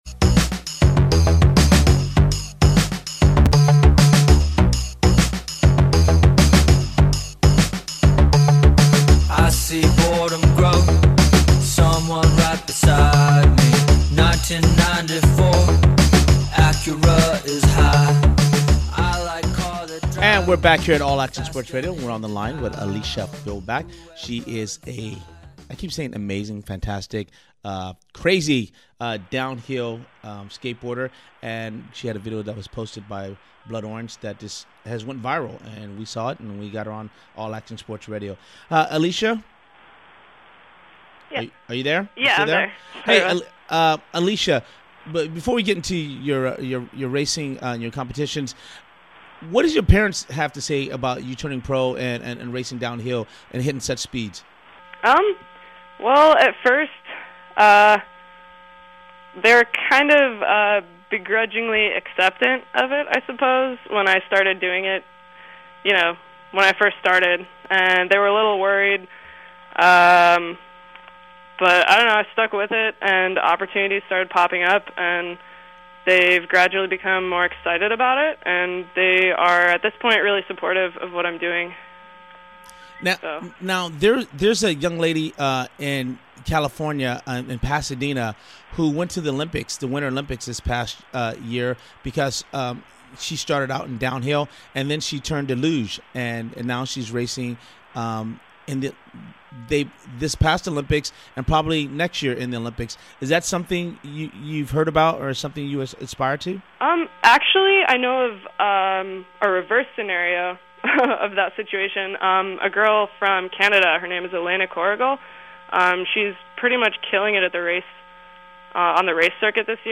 All Action Sports Radio / AASR Athlete Interview